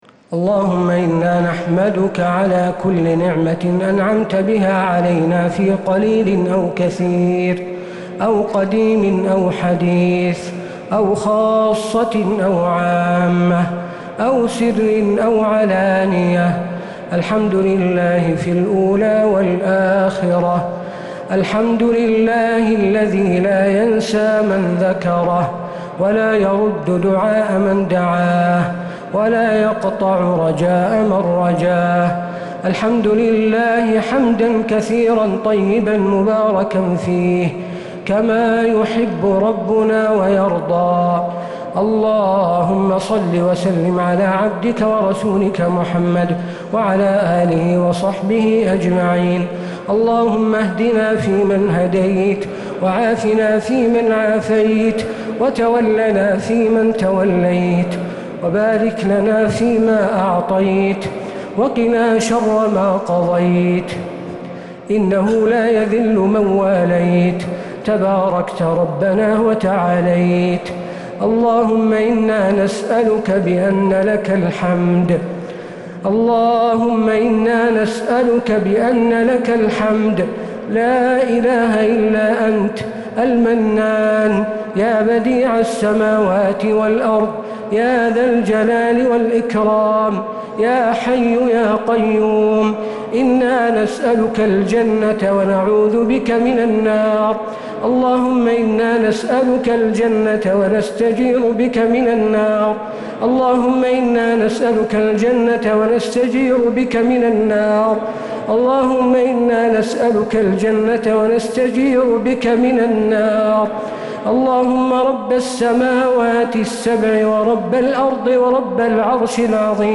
دعاء القنوت ليلة 10 رمضان 1446هـ | Dua 10th night Ramadan 1446H > تراويح الحرم النبوي عام 1446 🕌 > التراويح - تلاوات الحرمين